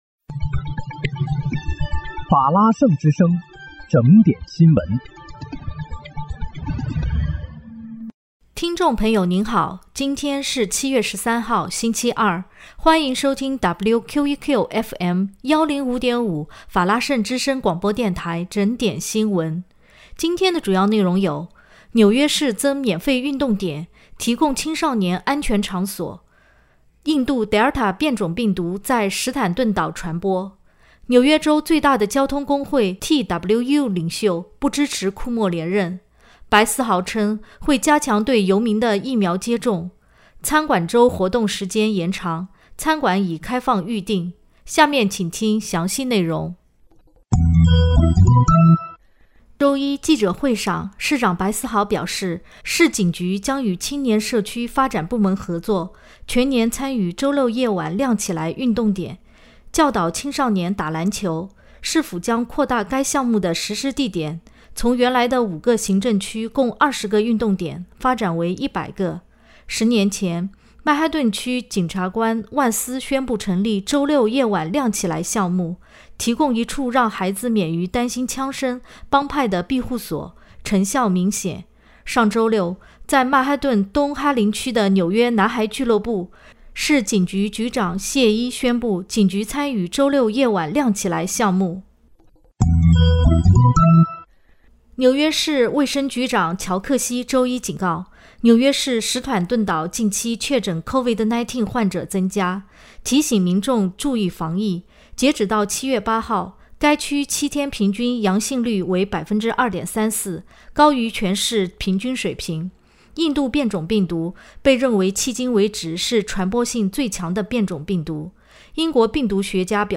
7月13日（星期二）纽约整点新闻